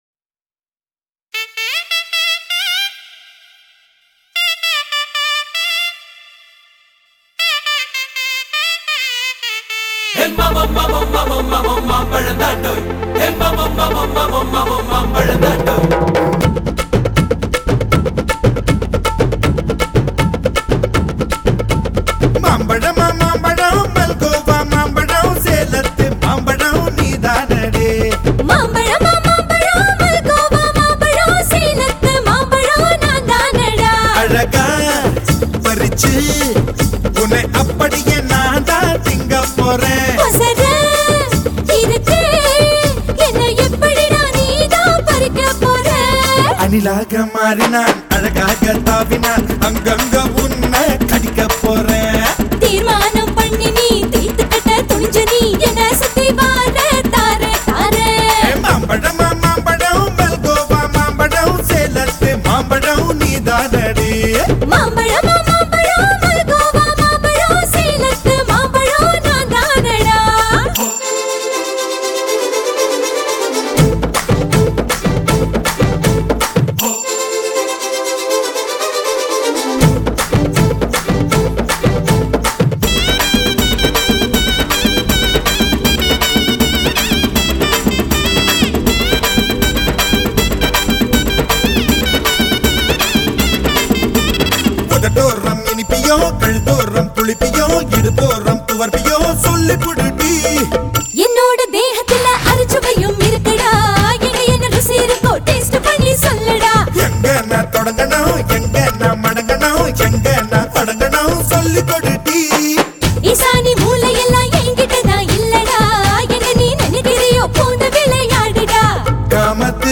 آهنگ شاد و شنیدنی هندی